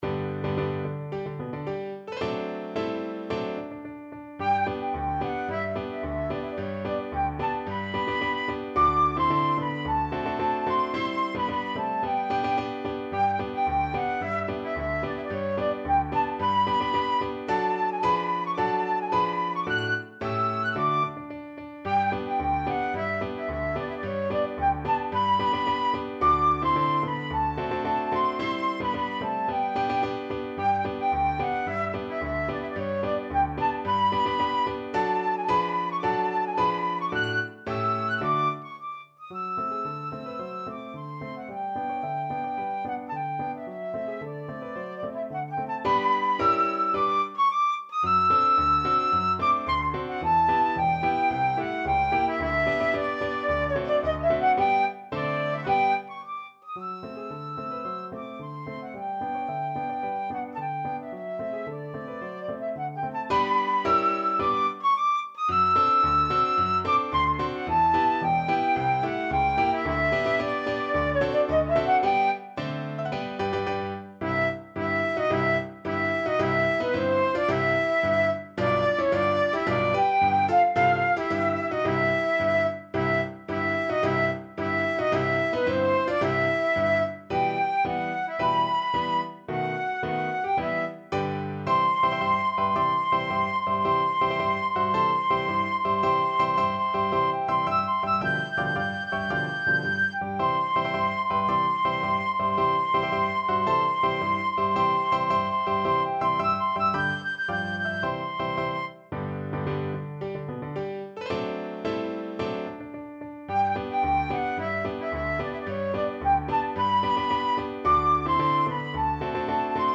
Classical Komzak, Karel Erzherzog Albrecht March Flute version
Flute
March =c.110
G major (Sounding Pitch) (View more G major Music for Flute )
2/2 (View more 2/2 Music)
Classical (View more Classical Flute Music)